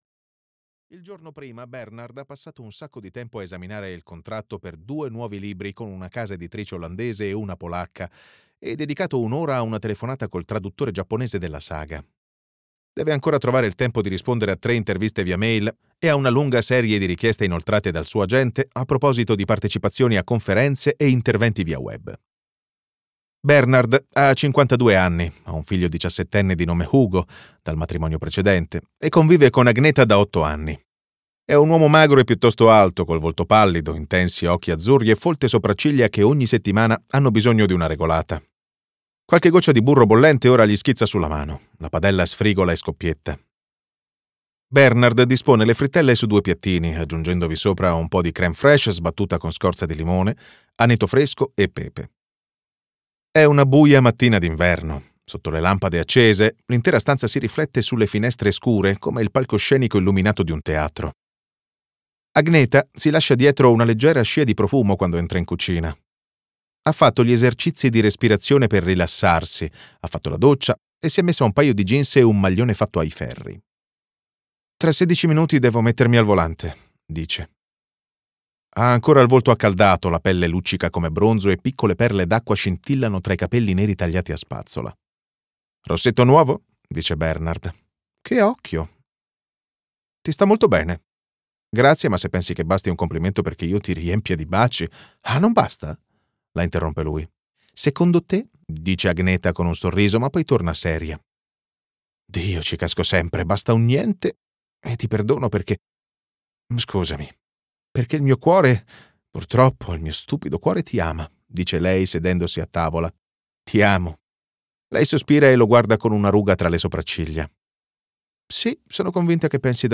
"Il sonnambulo" di Lars Kepler - Audiolibro digitale - AUDIOLIBRI LIQUIDI - Il Libraio